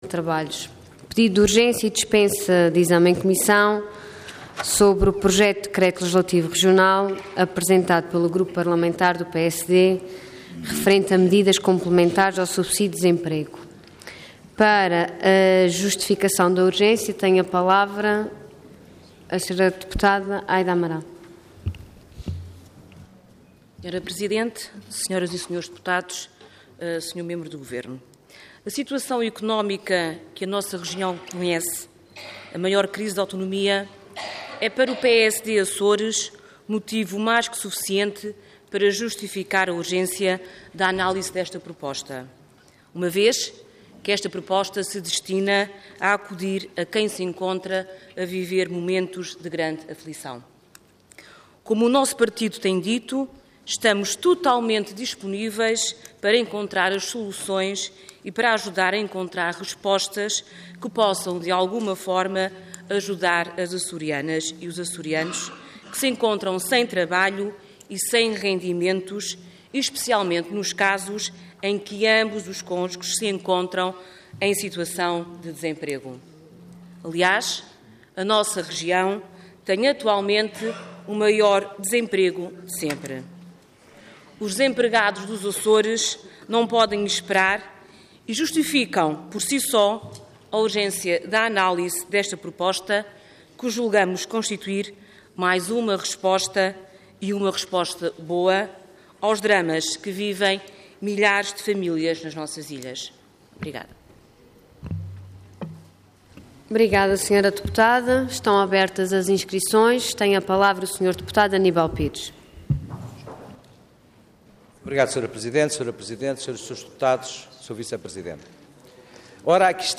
Intervenção Debate de urgência Orador Aida Amaral Cargo Deputada Entidade PSD